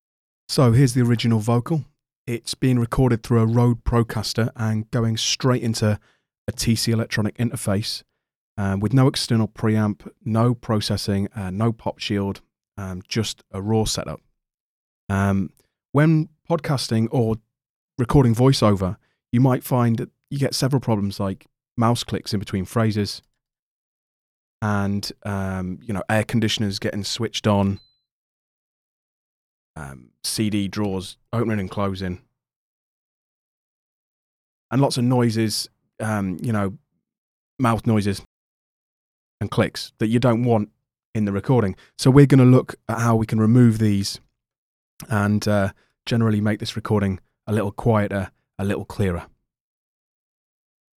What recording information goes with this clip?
The combination of the manual noise removal and gating makes for a really quiet and clean vocal take. One thing I noticed was that the Logic gate seems to introduce some very small random clicks to the sound, so apologies for this. The audio with some gating and manual noise removal.